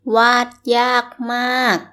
∧ waad ∧ yaag ∧ maag